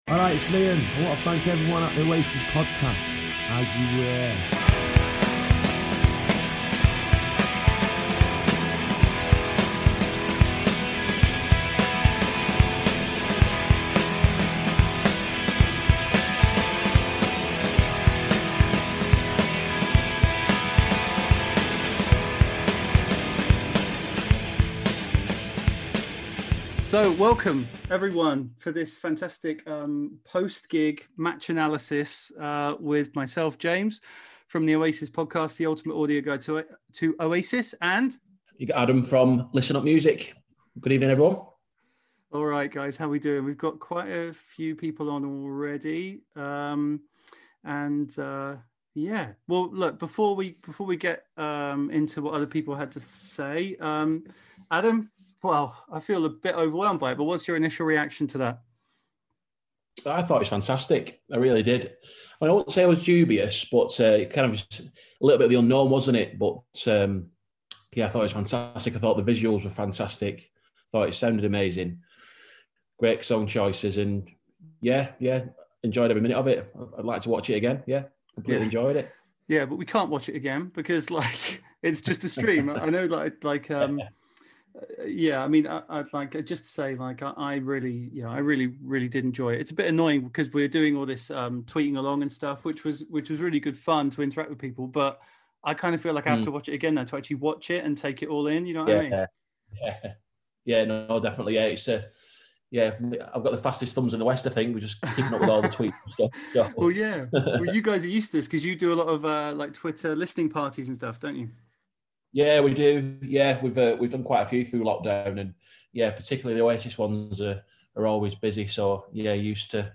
Today's episode is a phone in discussion following tonight's Liam Gallagher Down By The River Thames gig!